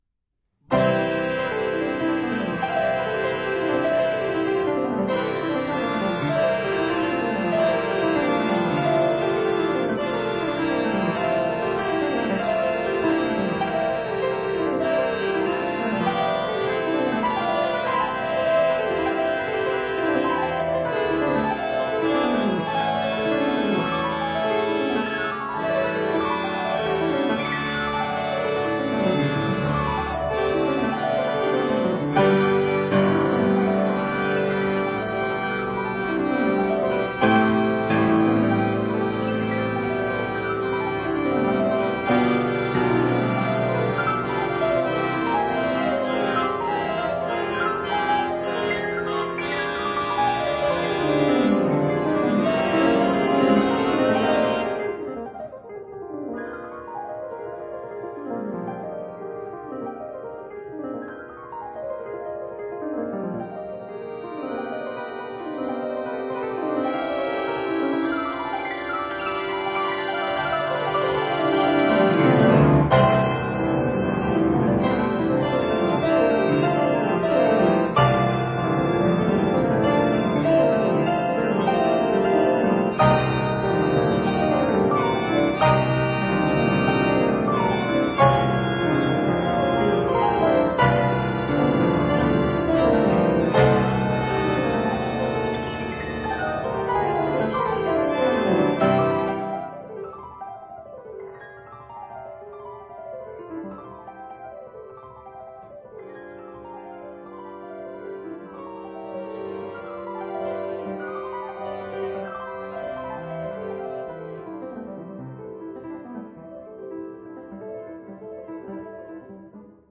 鋼琴提供節奏和精湛的細節，簧風琴則讓聲音有了廣度，
錄音使用的樂器包括一架1889年的架美麗簧風琴，
以及，聲音聽來高貴的1902年的Érard三角鋼琴。